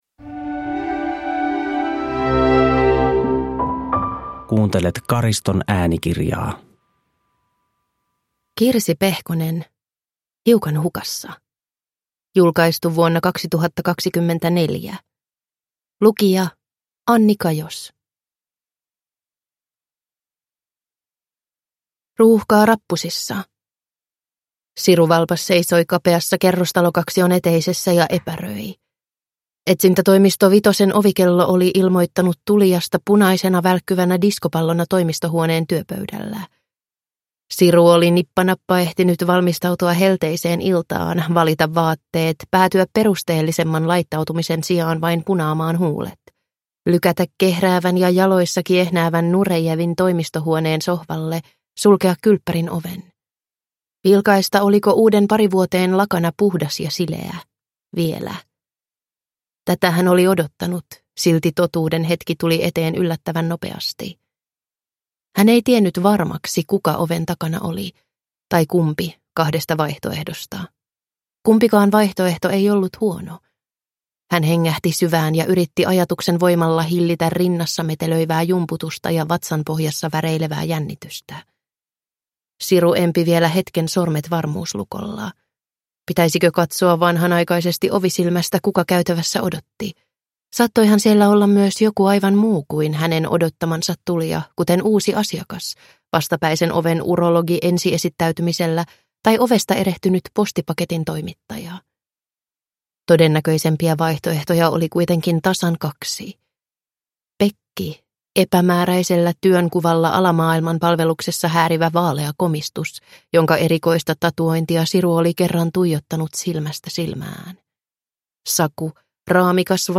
Hiukan hukassa (ljudbok) av Kirsi Pehkonen